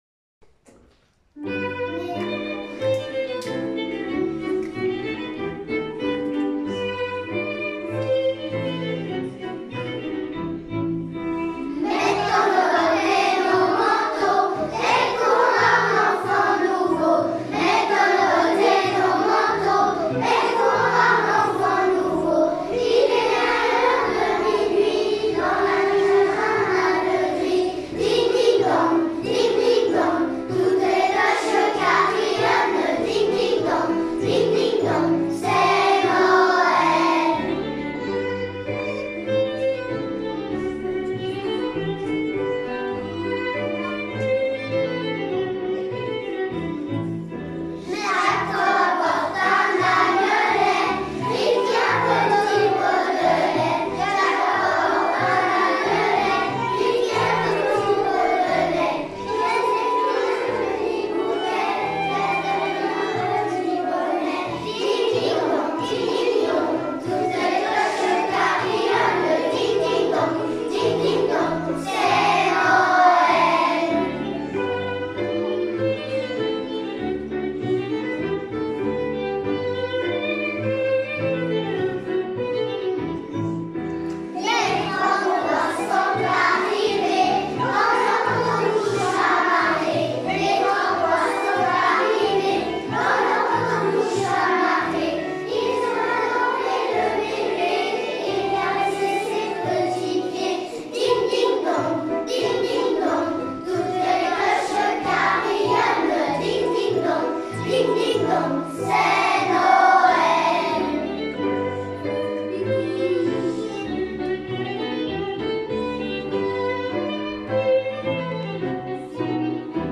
2021-22 : “Chantée de Noël”, les classes de Corcelles
Groupe 4 : classes 1-2P41, 5P43 et 3P41